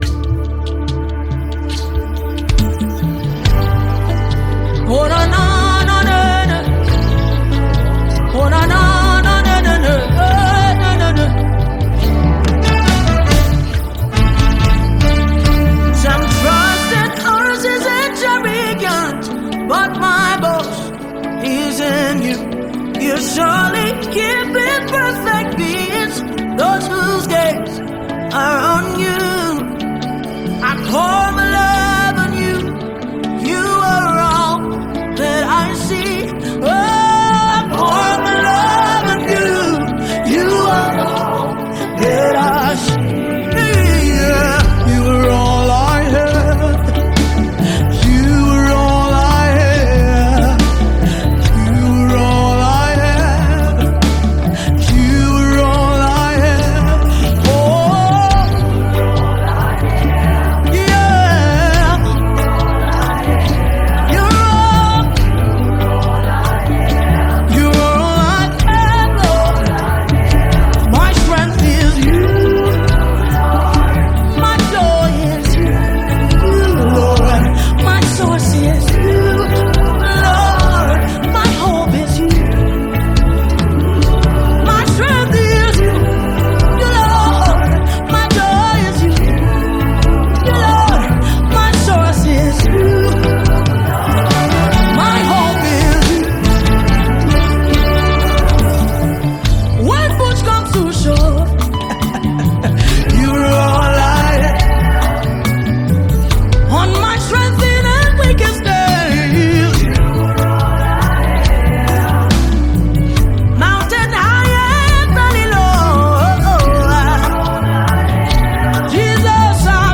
Talented Gospel Singer and songwriter
gospel worship single